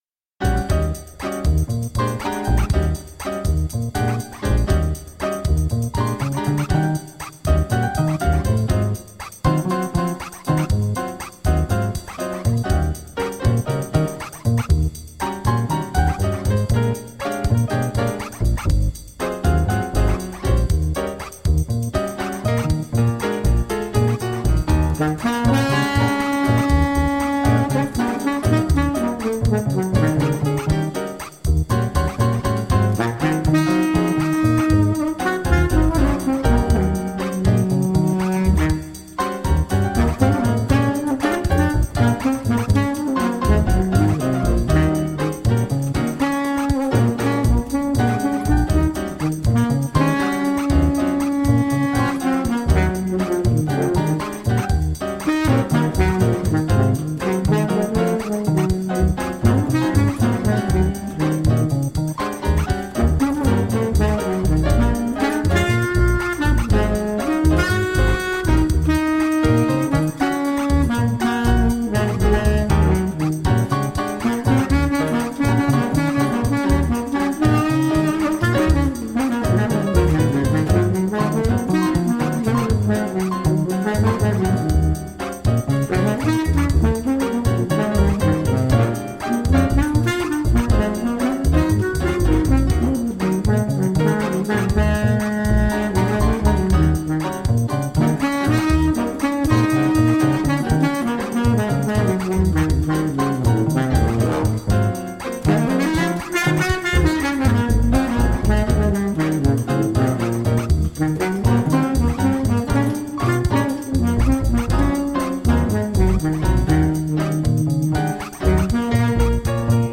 Toe teasin' jazz tunes for all occasions.